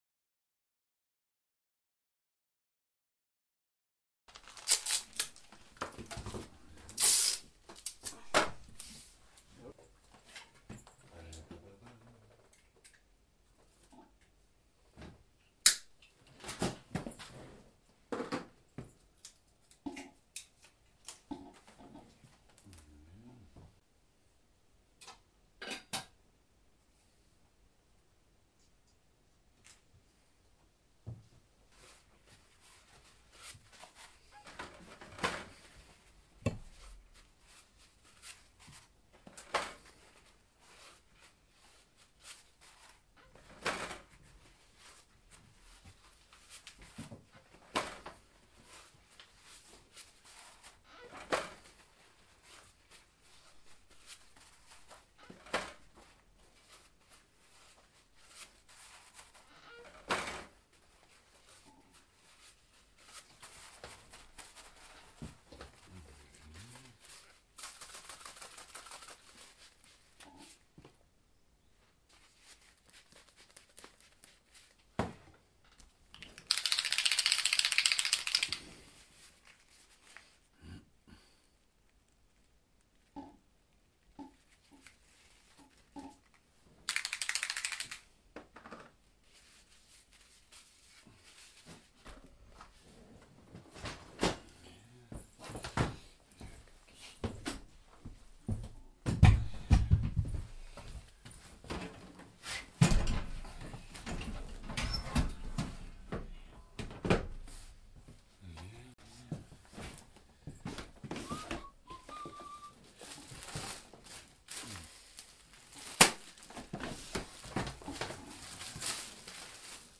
На этой странице собраны звуки работы тату-машинки в разных режимах: от мягкого жужжания до интенсивного гудения.
Шум работы тату мастера